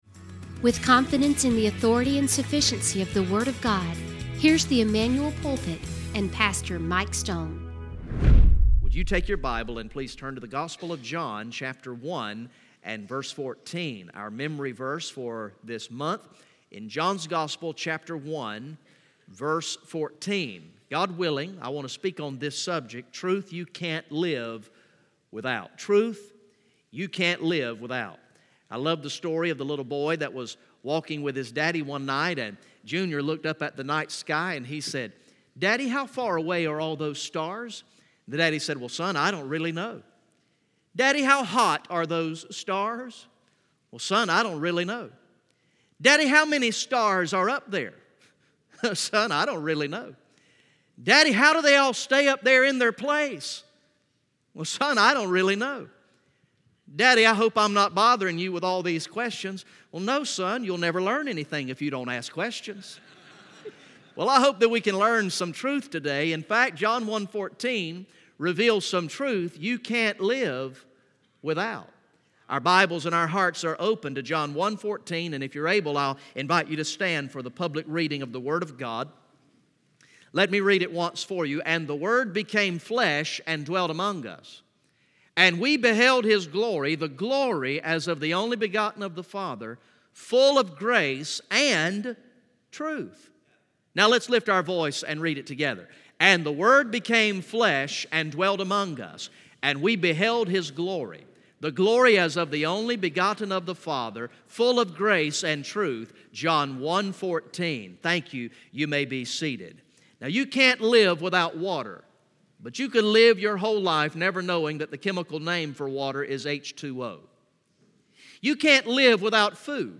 From the morning worship service on Sunday, December 2, 2018